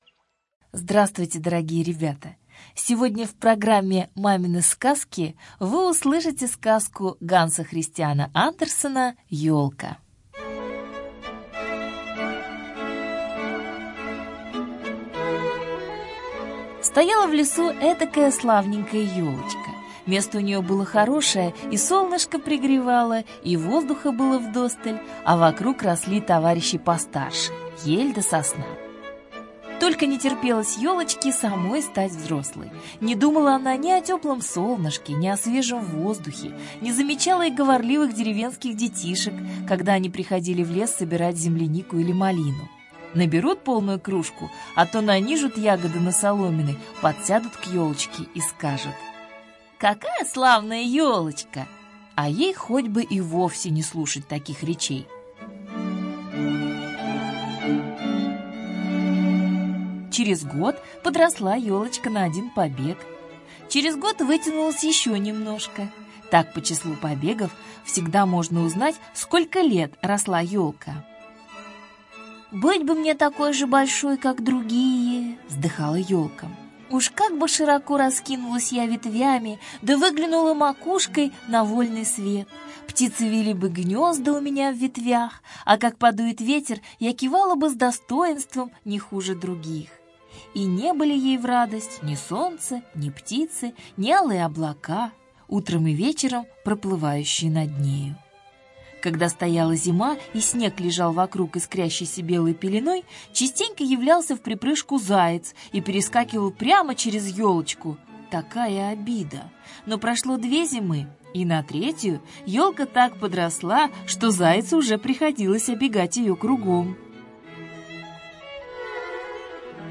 Ель - аудиосказка Андерсена. История одной елки, что росла в лесу от самого ее детства до кончины в виде дров в печи.